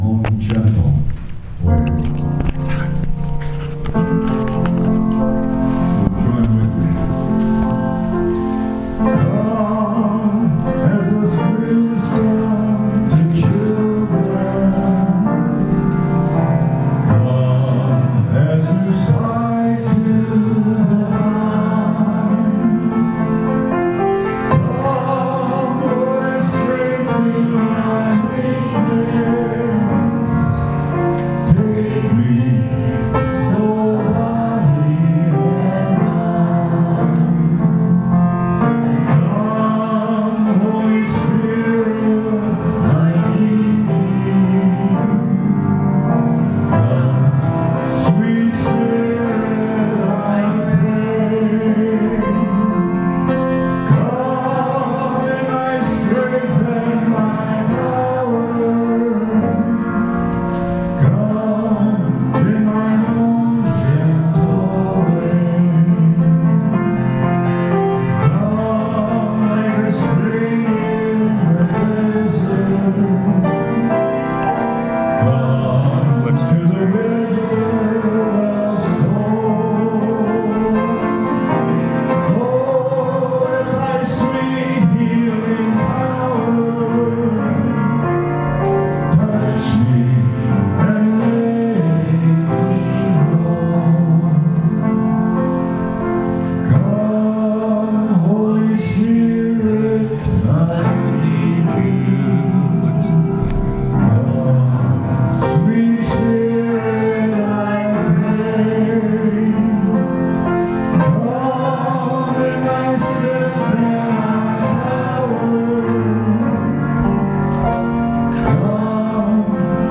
Bible Text: John 7: 37-39 | Preacher